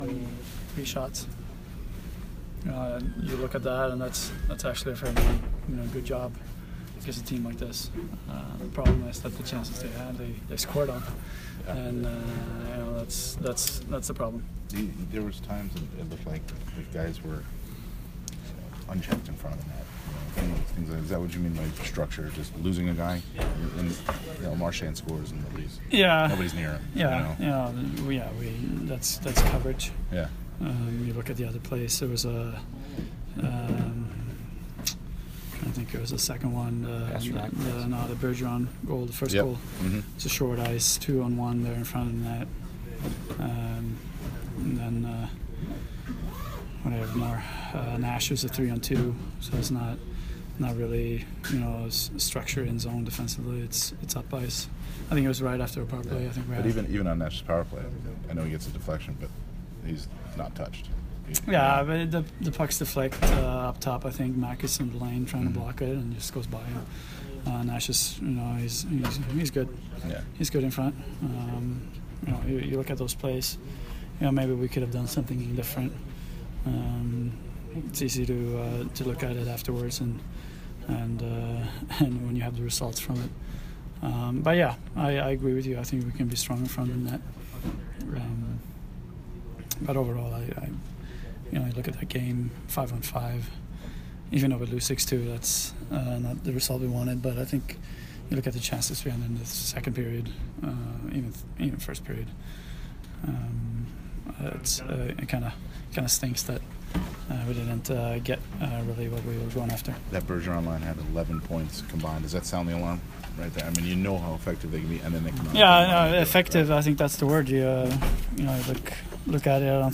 Anton Stralman post-game 4/28